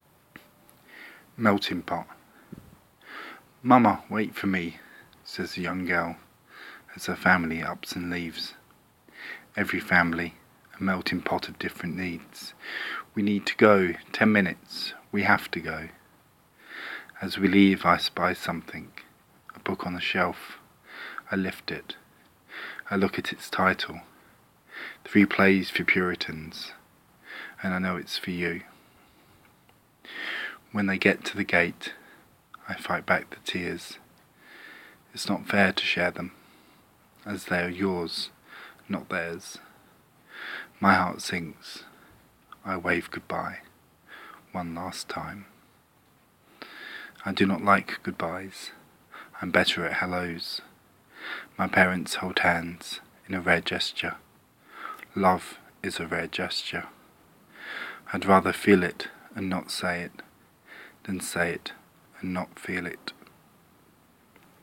Poetry